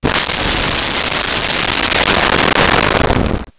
Thunder Sweep
Thunder Sweep.wav